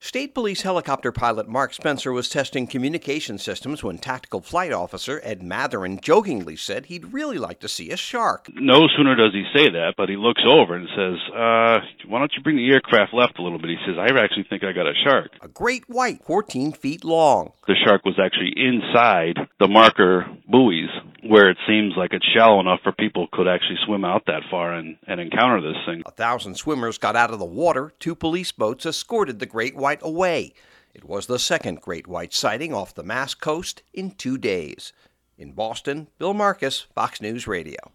PICKS UP THE STORY: